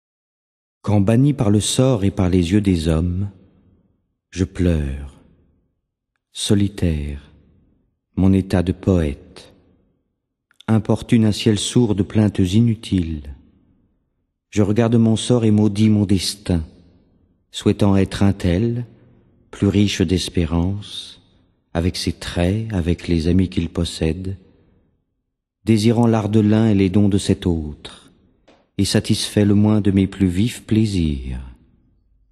Voix, chant, piano